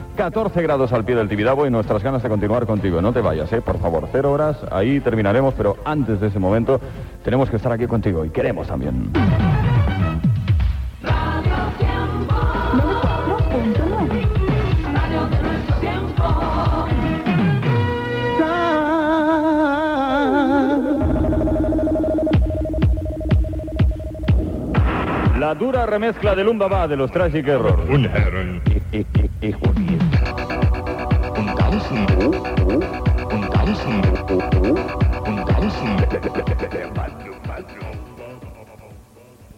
73074f1601fe825a358bbd5f2b0a2c30b751923b.mp3 Títol Radio Tiempo Emissora Radio Tiempo Titularitat Privada local Descripció Temperatura, indicatiu i tema musical.